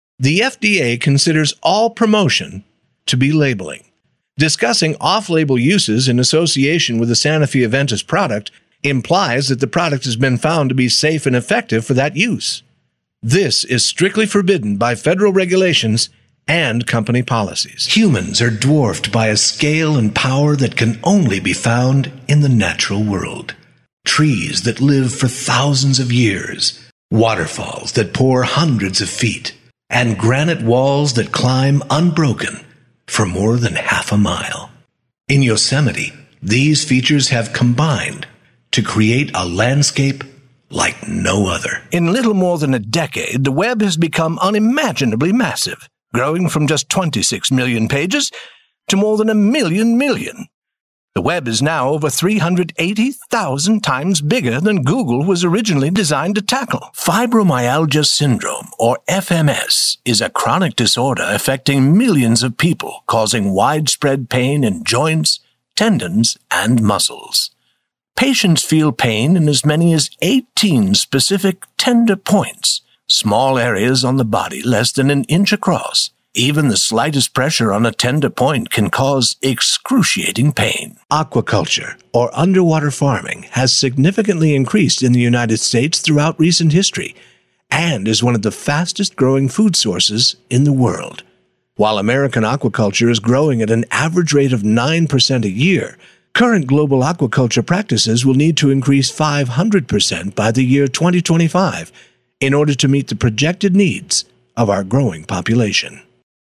A VOICE THAT\'S COMPLETELY DIFFERENT - WARM, GENUINE, FRIENDLY. APPROACHABLE, CONVERSATIONAL, YET AUTHORITATIVE, AND BELIEVABLE - AND A MASTER OF MANY DIALECTS AND ACCENTS, AS WELL.
Sprechprobe: eLearning (Muttersprache):